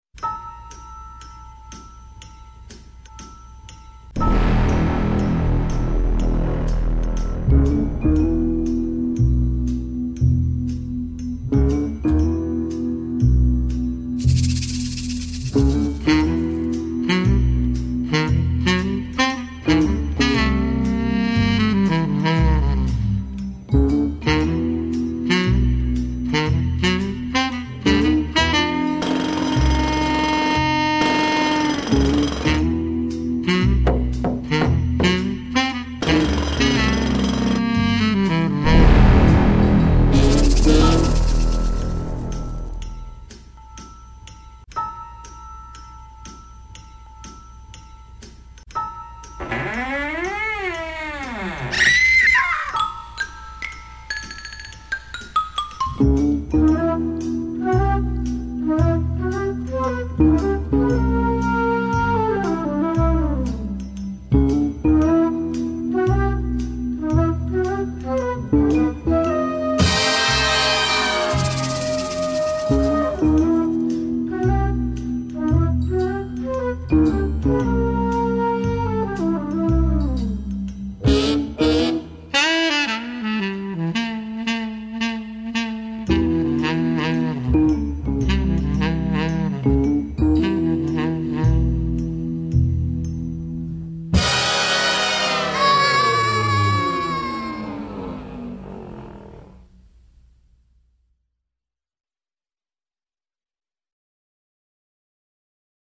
Фоновая музыка